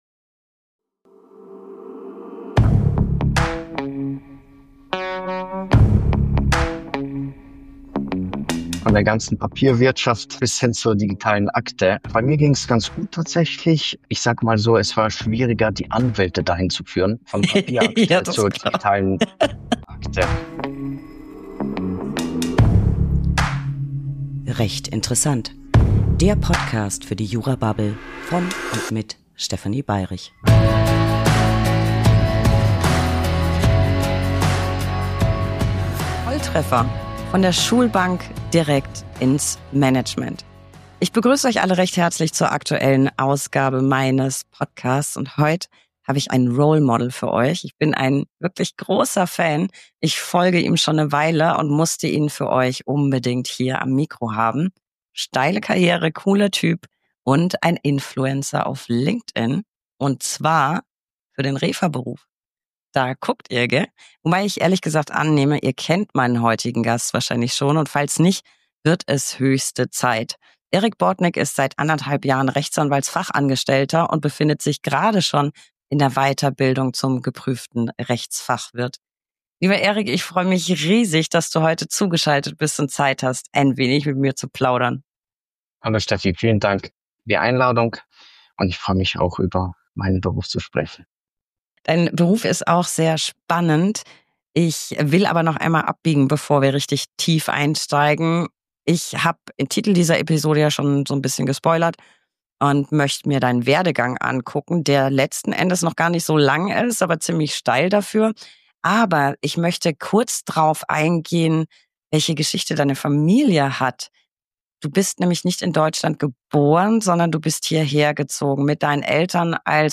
Was mache ich in einem Tango-Saal?
Und wo ginge das besser, als in einem Tango-Studio mit Publikum? In dieser Episode widmen wir uns dem Thema Macht!